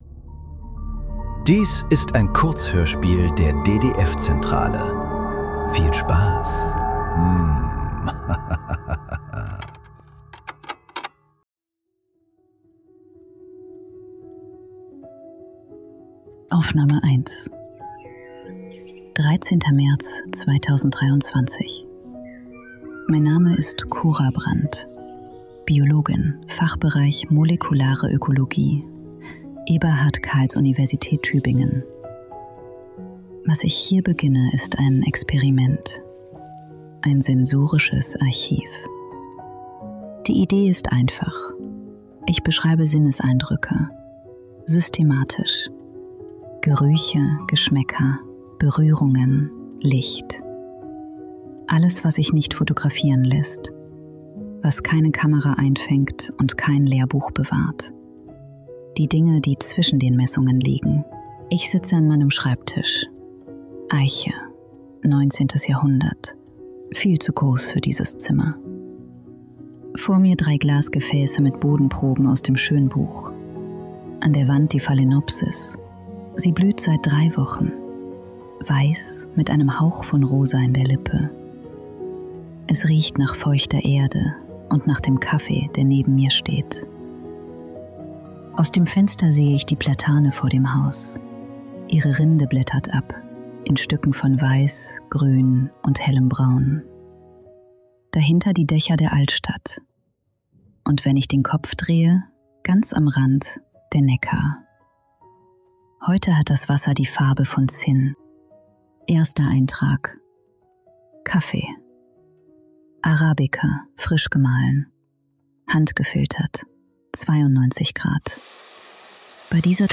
Für die, die ich sein werde ~ Nachklang. Kurzhörspiele. Leise.